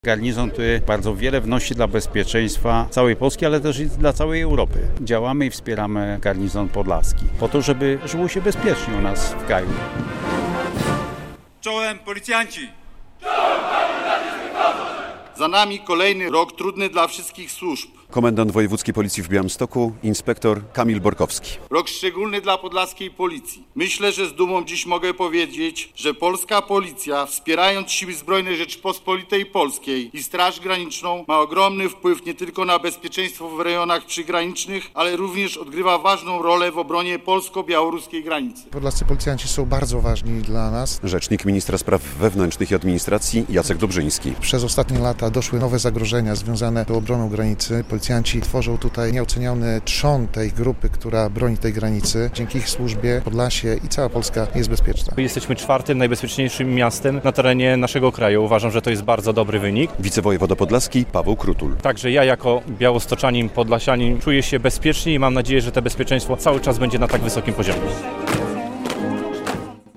Wojewódzkie i Miejskie Obchody Święta Policji w Białymstoku - relacja